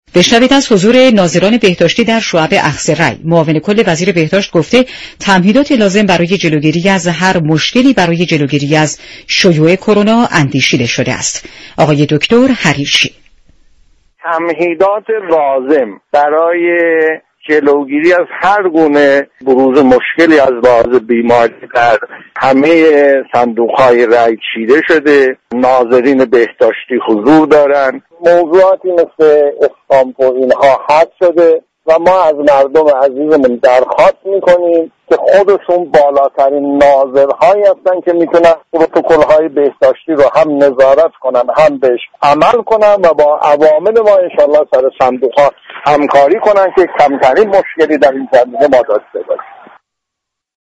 به گزارش شبكه رادیویی ایران، ایرج حریرچی قائم مقام وزارت بهداشت در بخش خبر رادیو ایران به تمهیدات بهداشتی انتخاباتی پرداخت و گفت: تمیهدات لازم برای جلوگیری از شیوع ویروس كرونا در شعبه های اخذ رای انجام شده و ناظرین بهداشتی در همه شعبه های حاضر هستند.